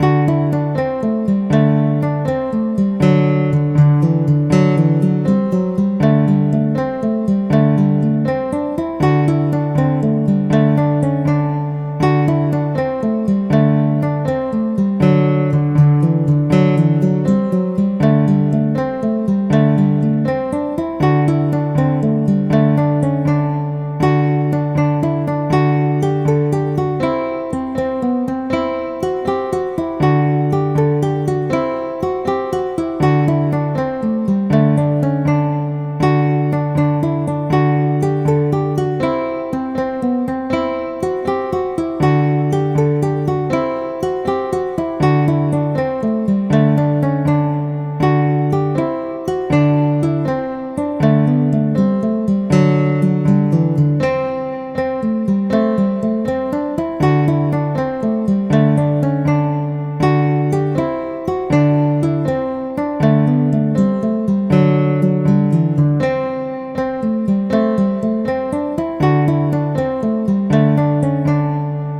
Banish Misfortune – Easy Picking